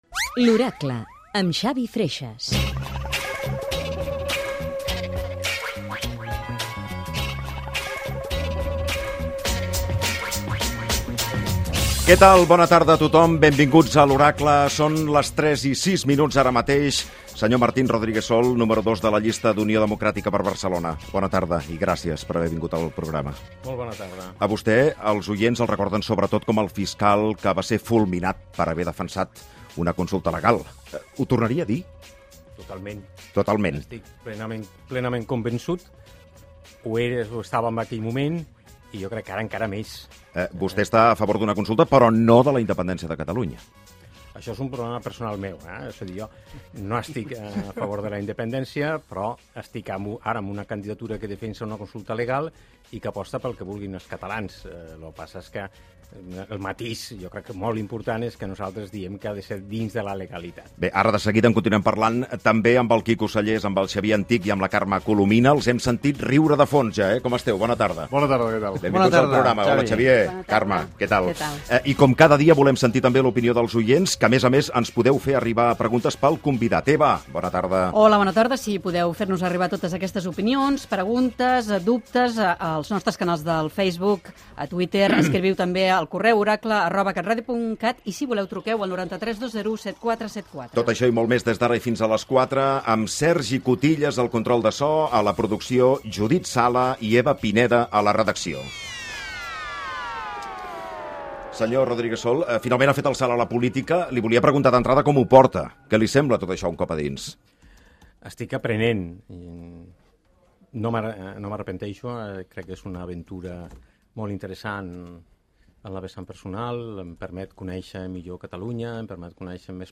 Careta del programa
Gènere radiofònic Informatiu